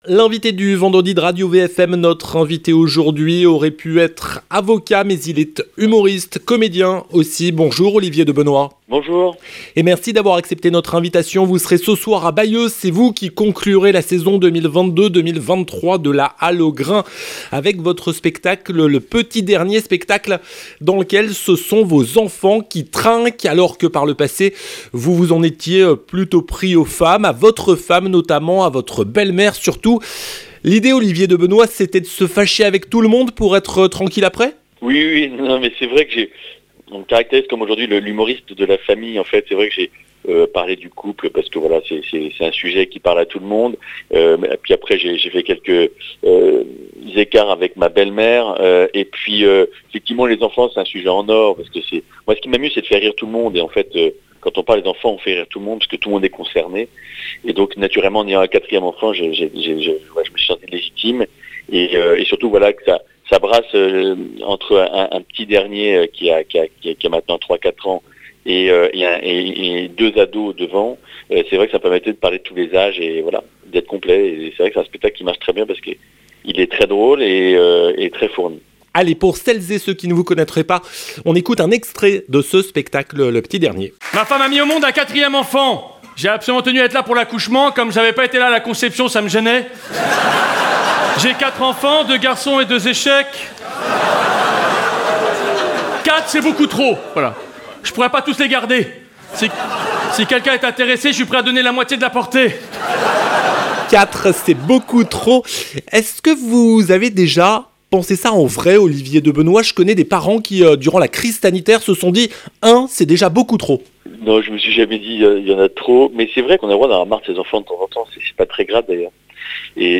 Avant de débarquer à Bayeux, ce vendredi soir, pour présenter son spectacle « Le Petit dernier » et clore la saison 2022-2023 de la Halle ô Grains, l'humoriste Olivier de Benoist sera l'invité du vendredi de Radio VFM ! Après avoir beaucoup parlé de sa belle-mère et de sa femme, Olivier de Benoist a décidé, dans ce spectacle, de se fâcher avec les seuls membres de sa famille qui le supportaient encore : ses enfants.